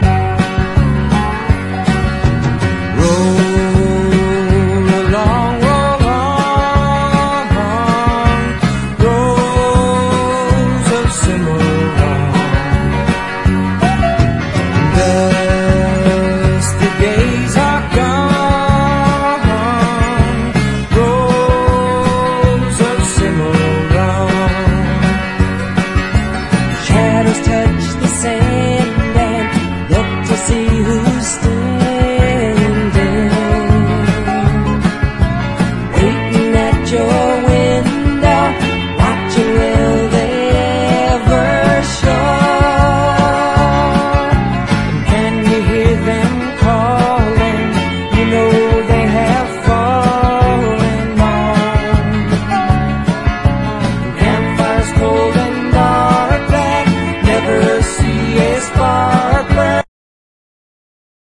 スタックス感も漂うカントリー・ソウル
ダルなファンキー・ソウル風の